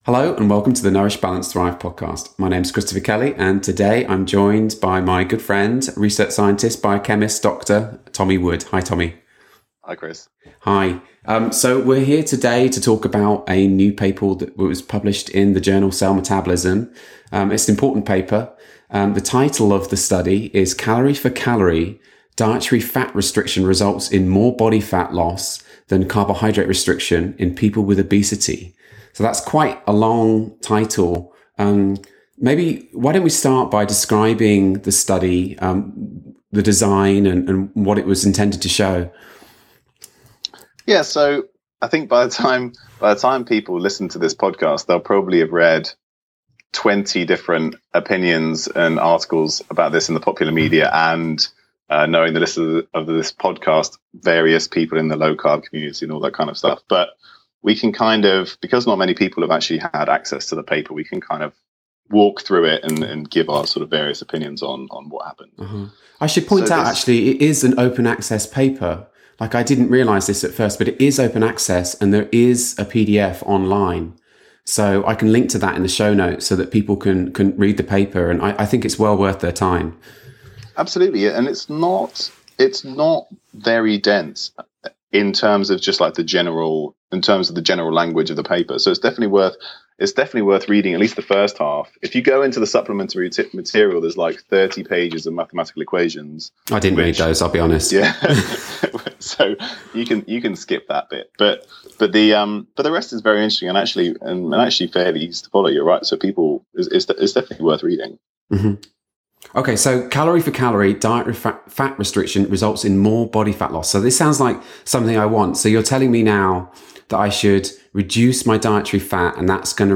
During the interview